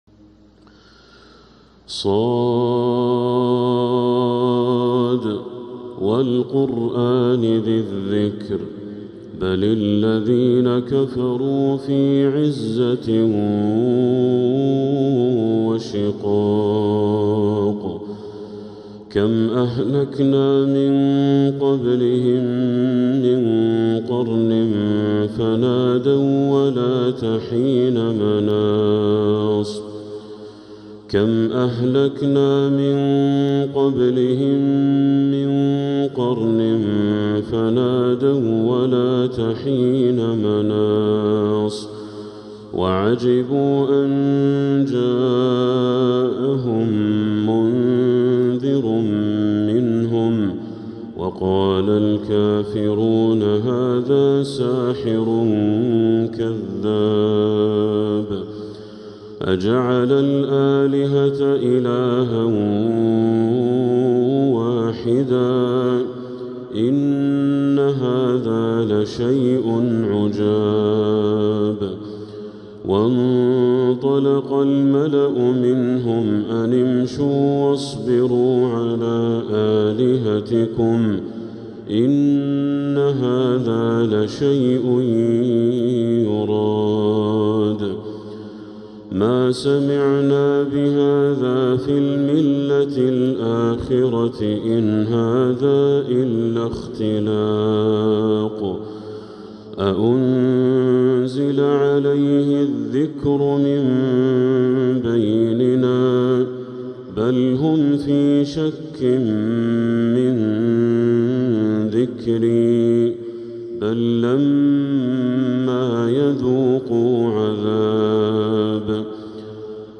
| سورة صٓ كاملة للشيخ بدر التركي من المسجد الحرام | Surat Saad Badr Al-Turki > السور المكتملة للشيخ بدر التركي من الحرم المكي 🕋 > السور المكتملة 🕋 > المزيد - تلاوات الحرمين